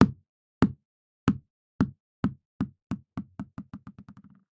ball2.ogg